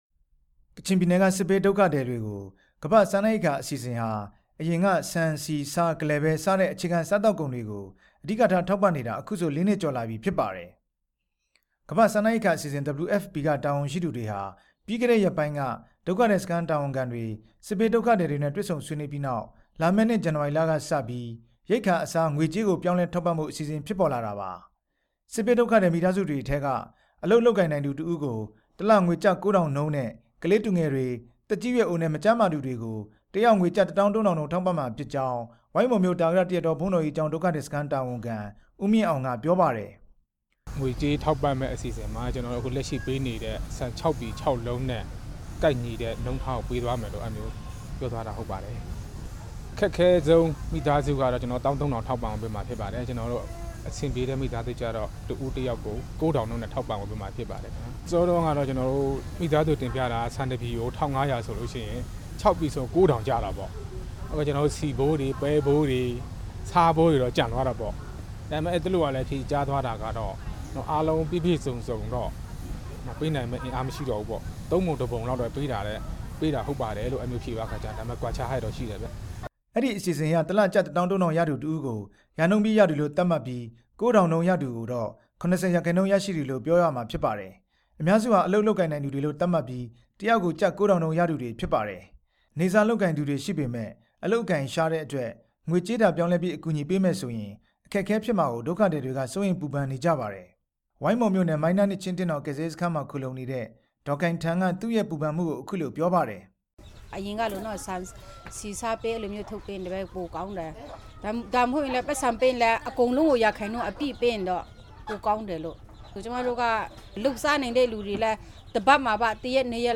အဲဒီလို ငွေကြေးပြောင်းလဲထောက်ပံ့မယ့် အစီအစဉ်ဟာ ဒုက္ခသည်တွေအတွက် အခက်အခဲတွေရှိလာနိုင်ကြောင်း ဒုက္ခသည်တွေနဲ့ စခန်းတာဝန်ခံတွေက ပြောကြပါတယ်။